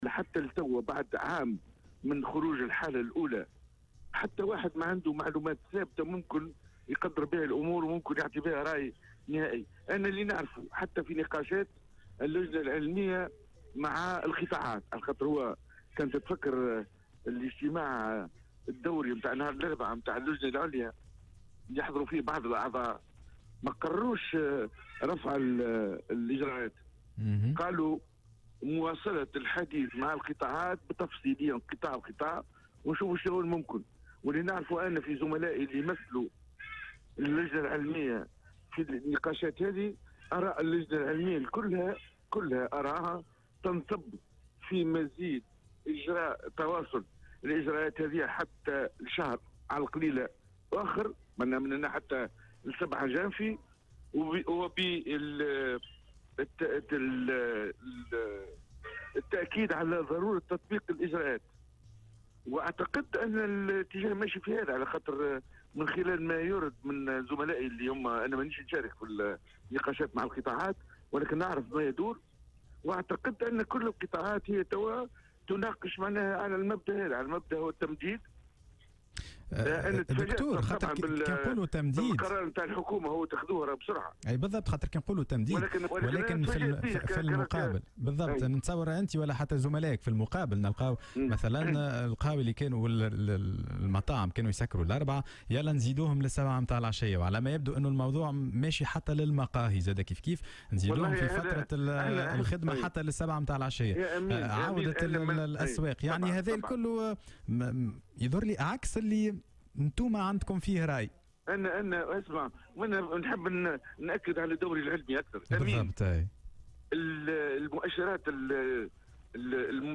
وقال في مداخلة له اليوم على "الجوهرة أف ام" إن اللجنة دعت في لقاءاتها على ضرورة تواصل العمل بالإجراءات التي تم اتخاذها للحد من انتشار العدوى على امتداد شهر آخر على الأقل.